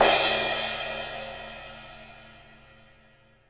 • Ambient Cymbal Sound F Key 01.wav
Royality free cymbal tuned to the F note. Loudest frequency: 4667Hz
ambient-cymbal-sound-f-key-01-tsD.wav